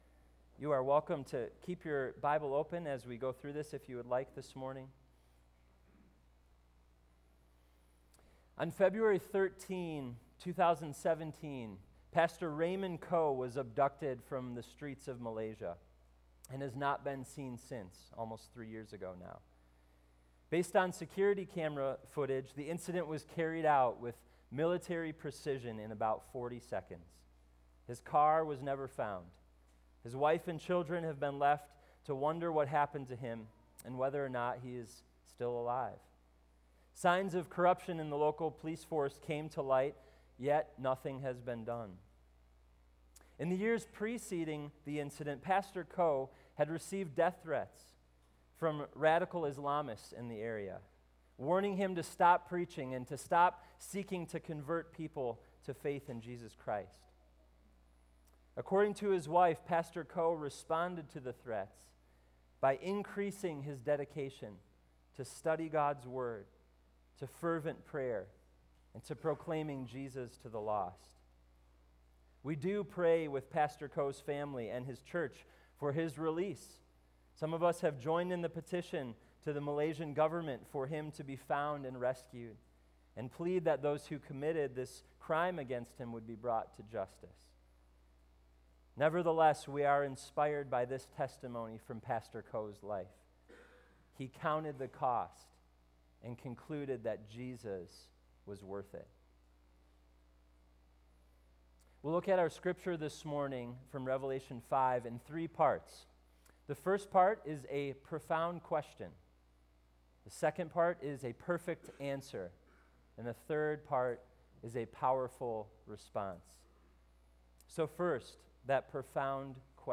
Sermon+Audio+-+The+Infinite+Worth+of+Jesus.mp3